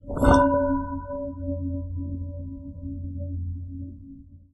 Metal Resonance
Metal Resonant Scrape Slide sound effect free sound royalty free Music